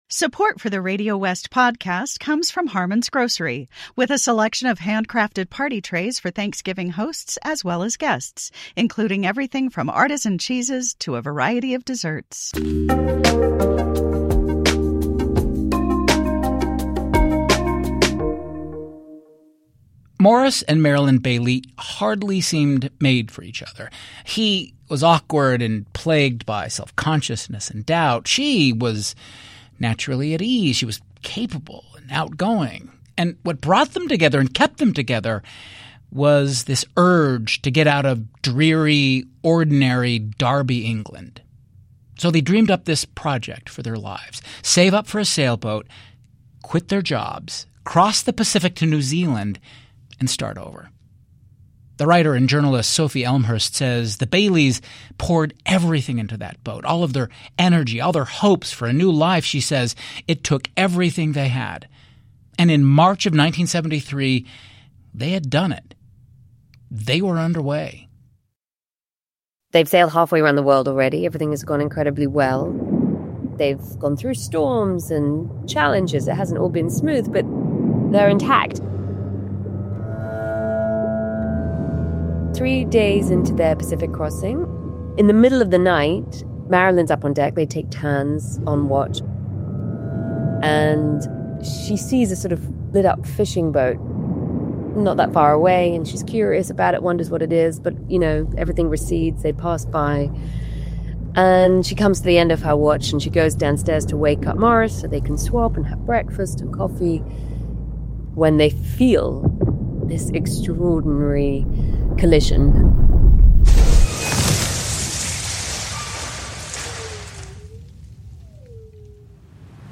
Join writers, filmmakers, scientists and others on RadioWest: A show for the wildly curious.